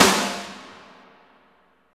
55.05 SNR.wav